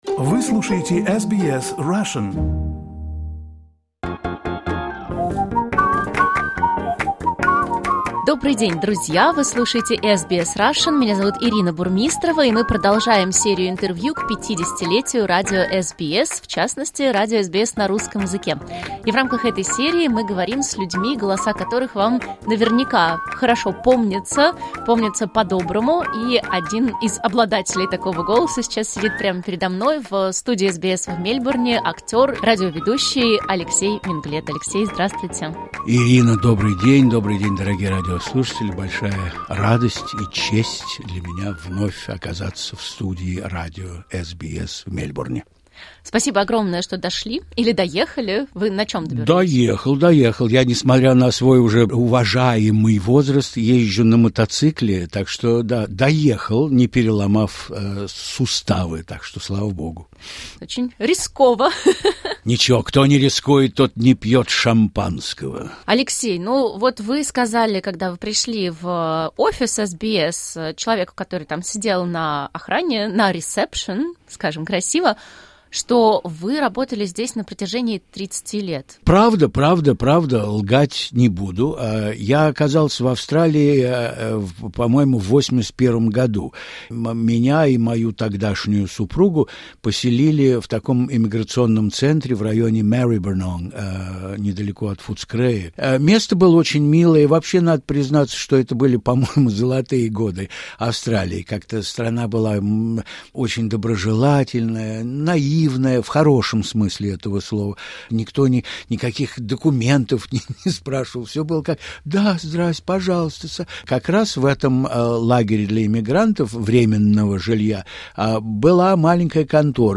В преддверии золотого юбилея мы говорим с ведущими SBS Russian разных лет.